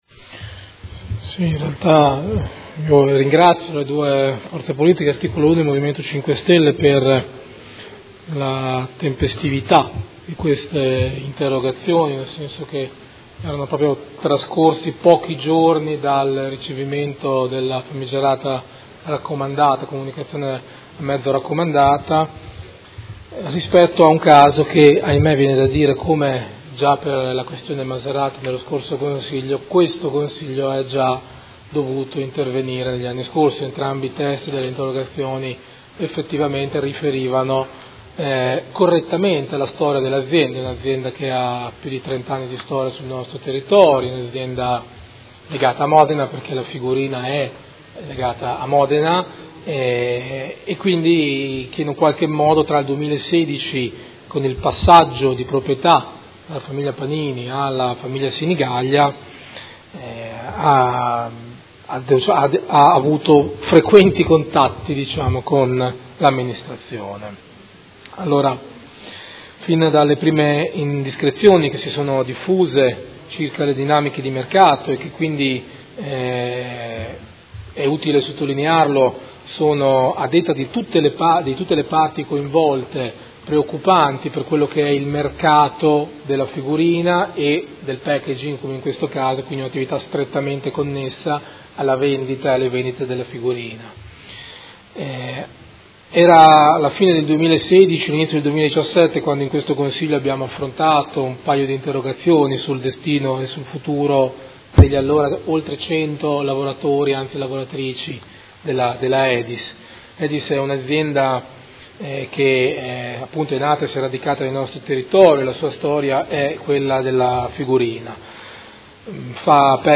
Seduta del 22/11/2018. Risponde a interrogazione dei Consiglieri Malferrari e Trande (Art1-MDP/Per Me Modena) avente per oggetto: La Edis comunica ai dipendenti il trasferimento della sede aziendale da Modena a Villa Marzana (Rovigo) e interrogazione dei Consiglieri Scardozzi, Fantoni, Bortolotti e Rabboni (M5S) avente per oggetto: Edis